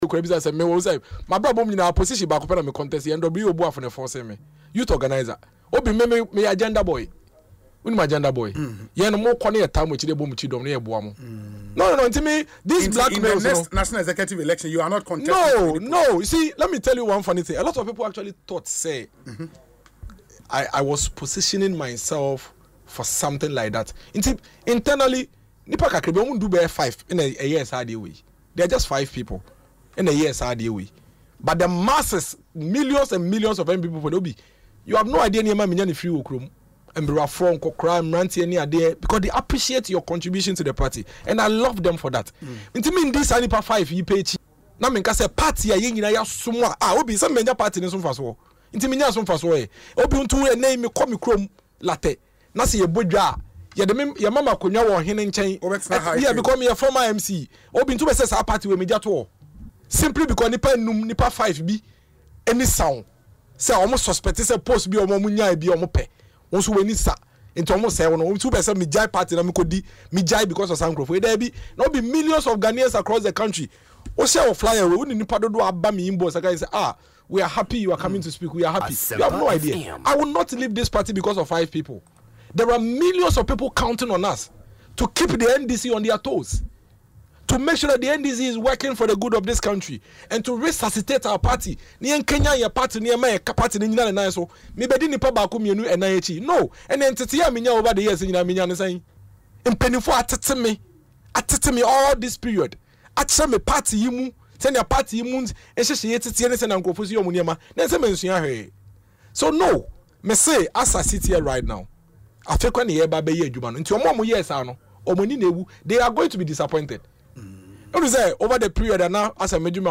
In an interview on Asempa FM’s Ekosii Sen show, he said that despite the challenges he has faced, he is not leaving the party over the actions of a few individuals.